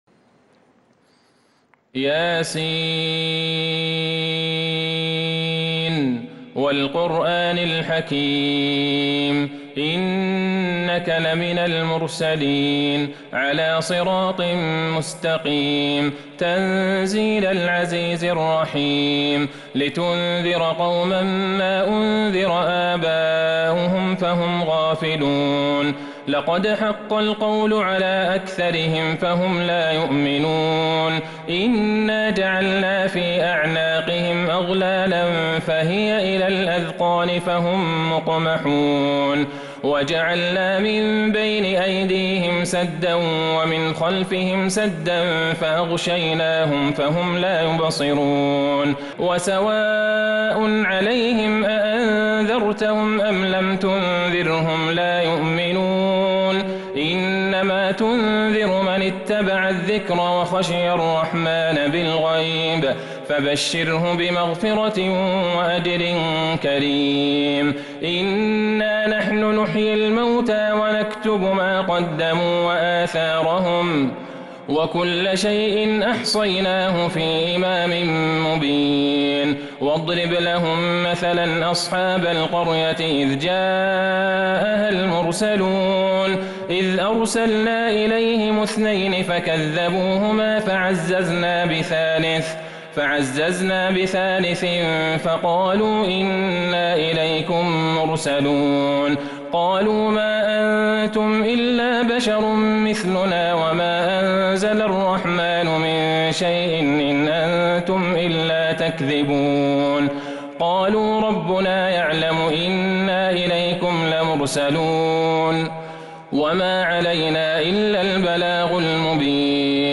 سورة يس كاملة من تراويح الحرم النبوي 1442هـ > مصحف تراويح الحرم النبوي عام 1442هـ > المصحف - تلاوات الحرمين